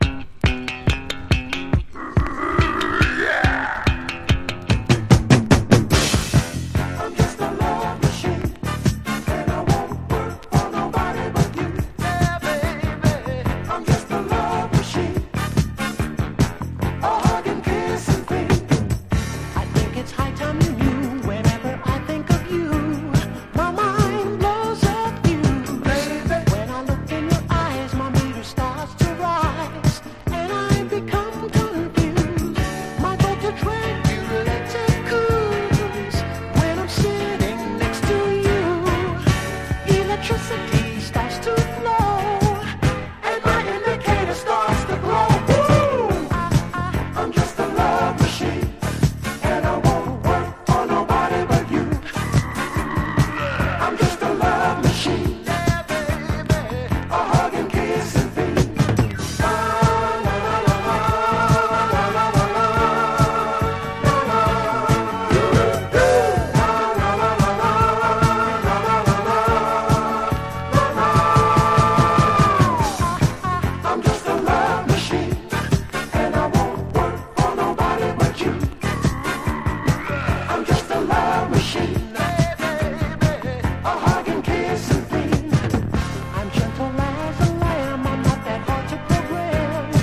FUNK / DEEP FUNK